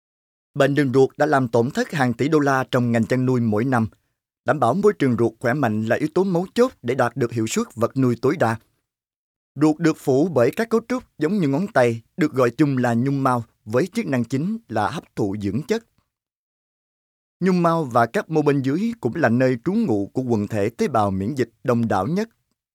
I have a rich, warm, deep and unique voice
Sprechprobe: Sonstiges (Muttersprache):